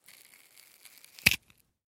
Звуки картошки
Звук разлома сырой картошки пополам